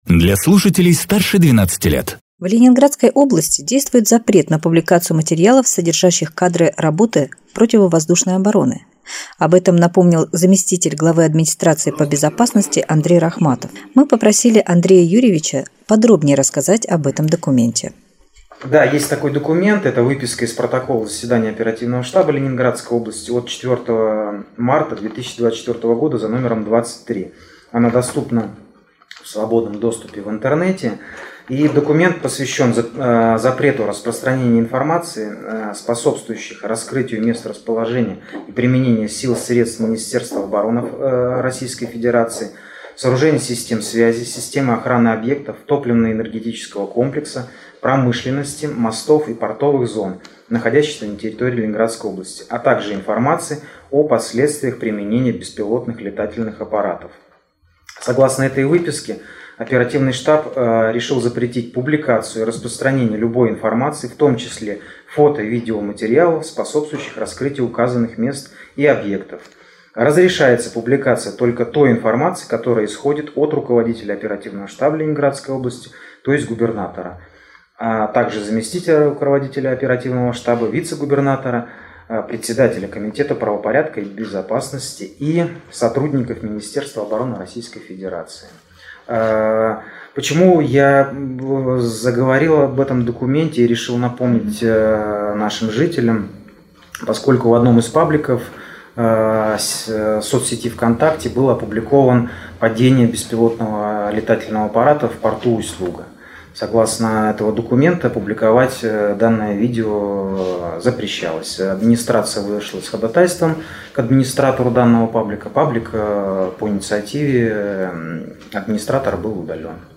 Интервью с заместителем главы администрации по безопасности Андреем Рахматовым